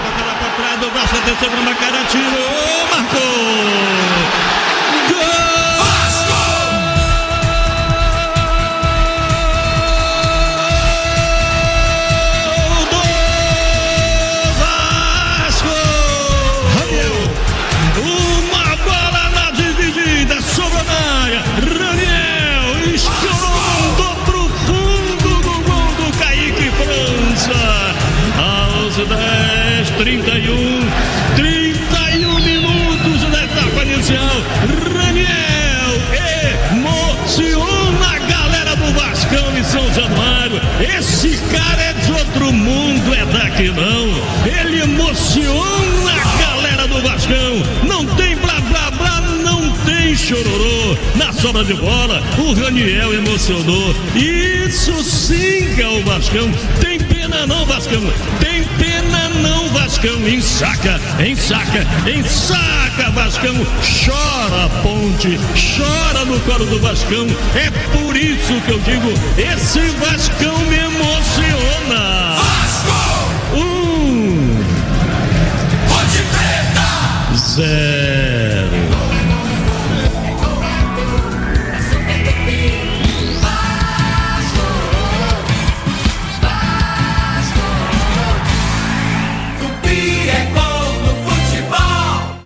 Ouça o gol da vitória do Vasco sobre a Ponte Preta pela Série B